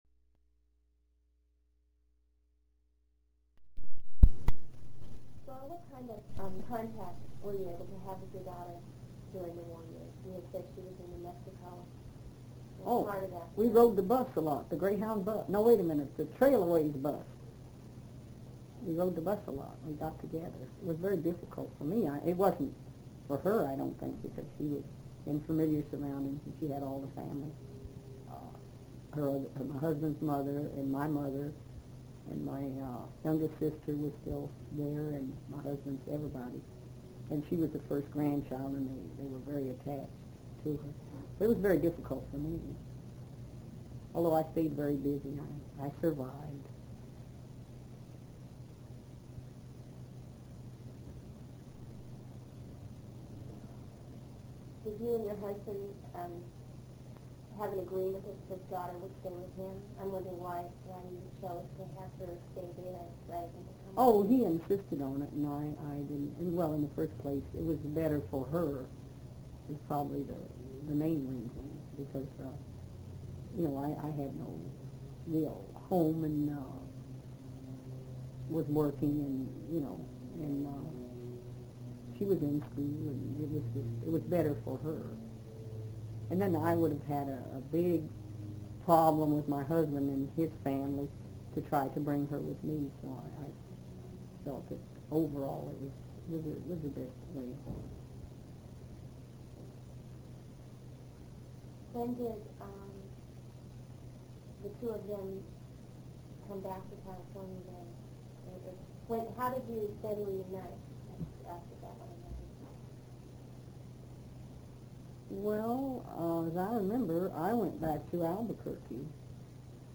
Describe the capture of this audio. The Center was a hub of activity, and although the interviews were conducted in the quietest space that could be found, there was constant background noise and interruptions.